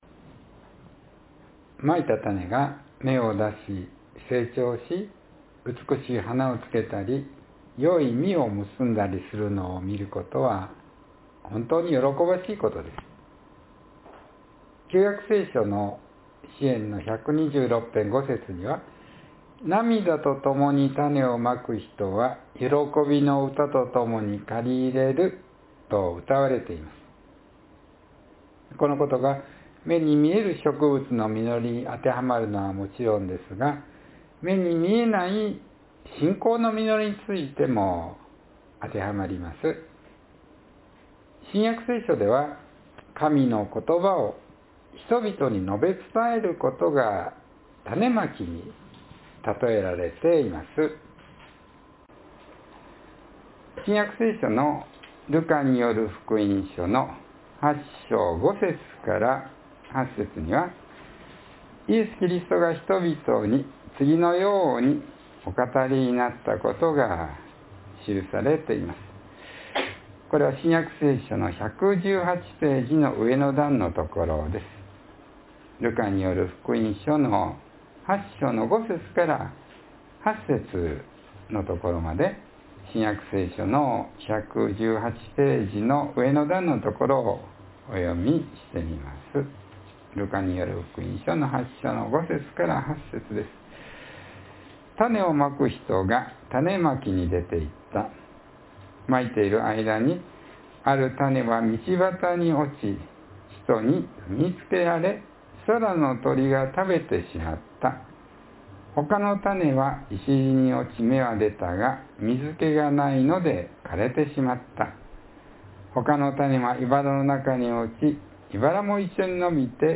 （2月1日の説教より）